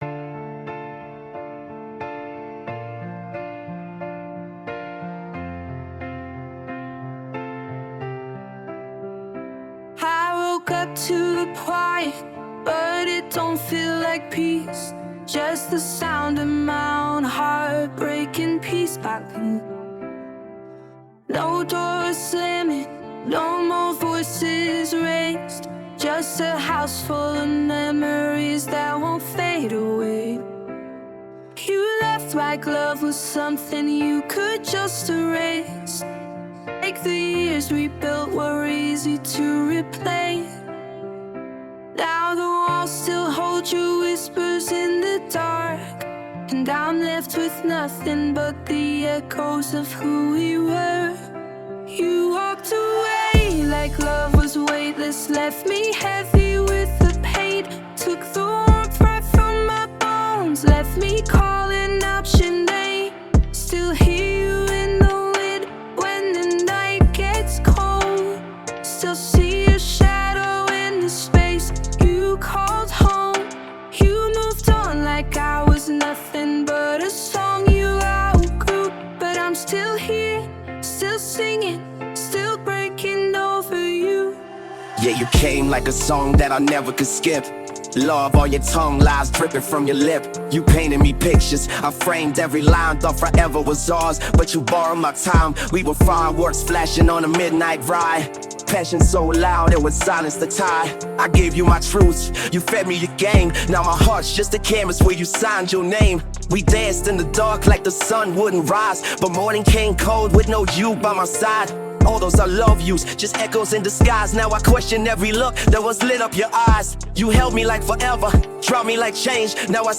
Instrumental, Remix, Mix & Mastering by Me
This is not official Song, this is REMIX!